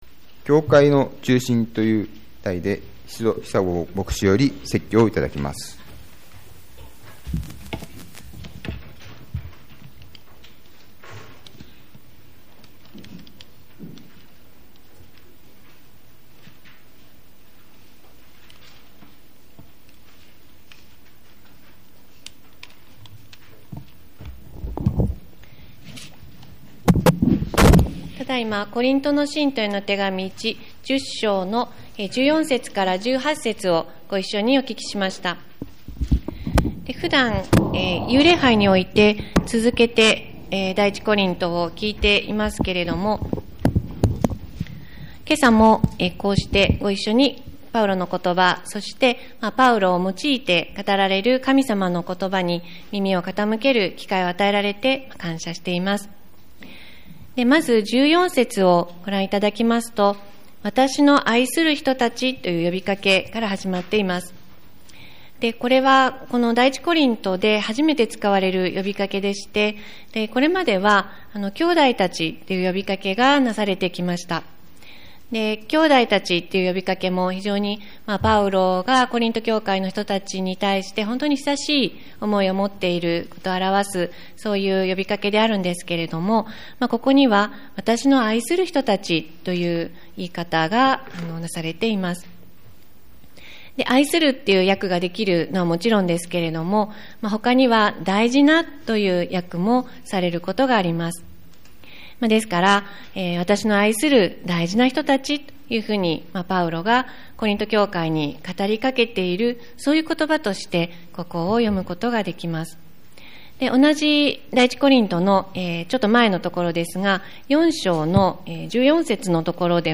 教会の中心 2017年11月第3主日礼拝